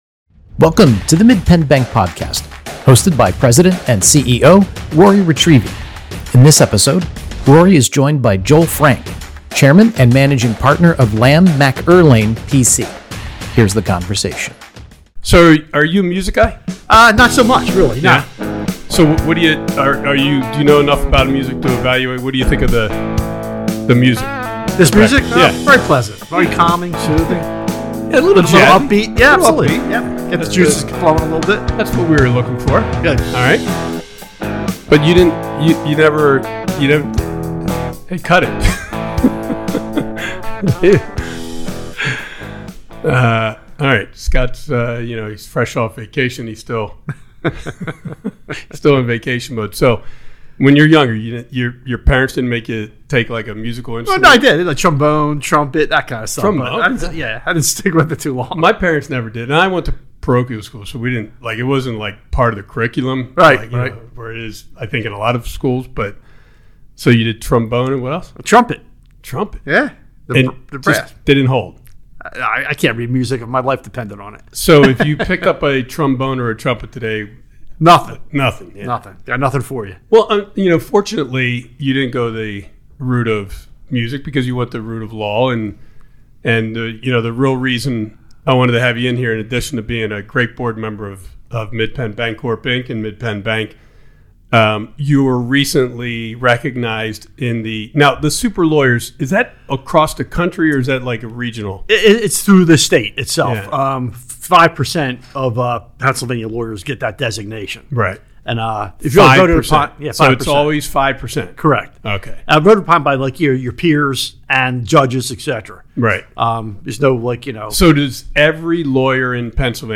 in depth conversation